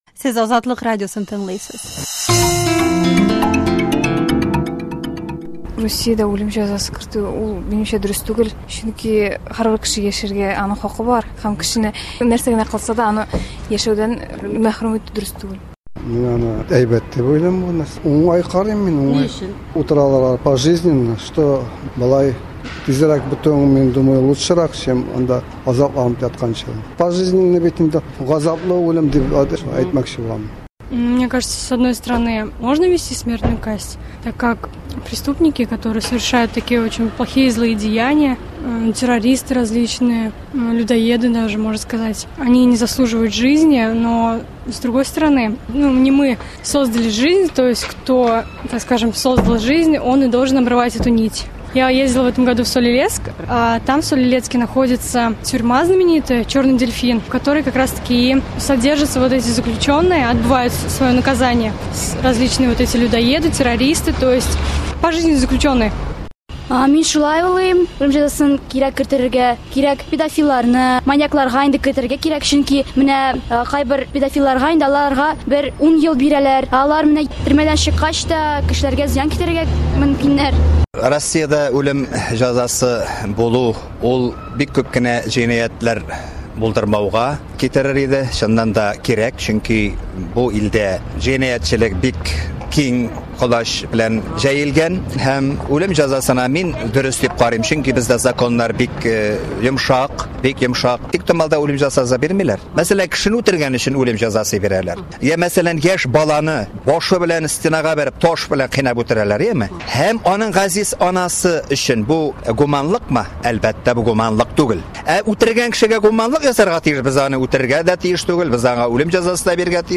Açıq mikrafon